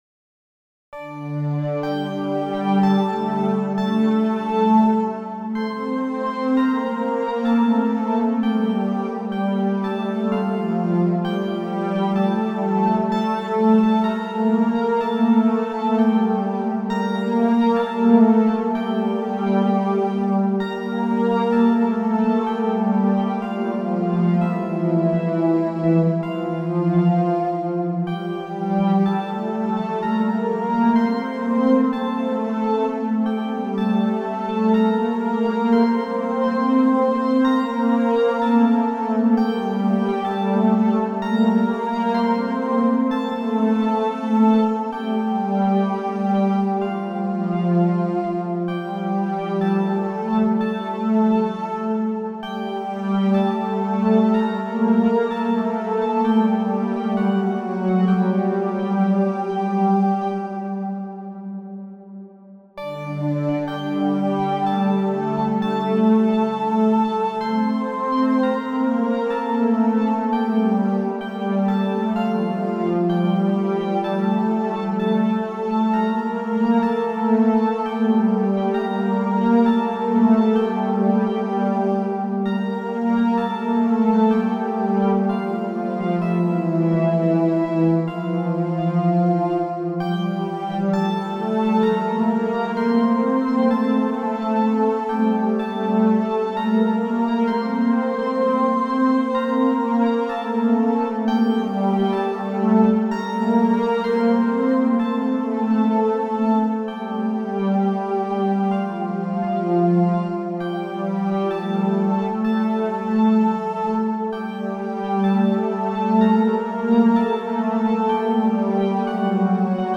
Byzantine music – selected works, recordings and documentation
A comprehensive collection of Byzantine music works and chant recordings performed by the author, reflecting long-term artistic practice and research.